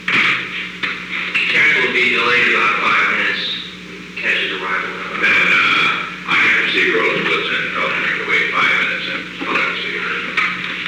Secret White House Tapes
Conversation No. 908-9
Location: Oval Office
The President met with an unknown man.